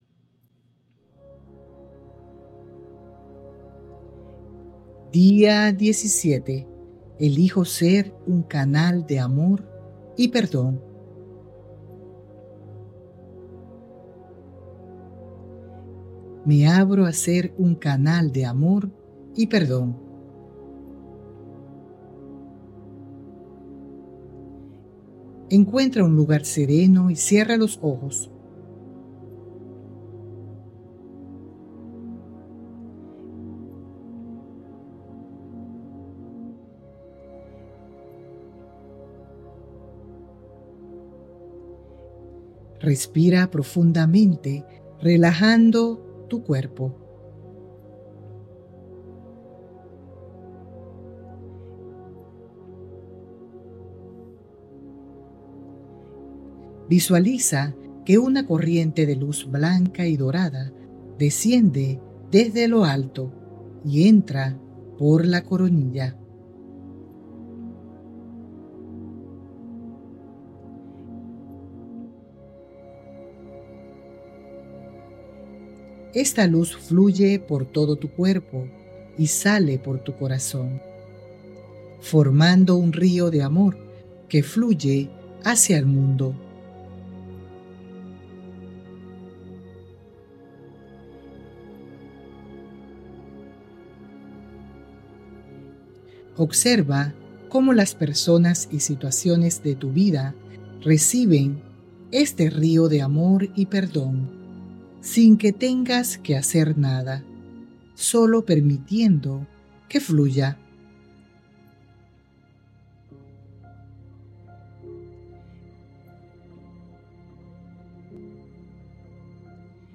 🎧 Meditación Guiada: «Me abro a ser un canal de amor y perdón»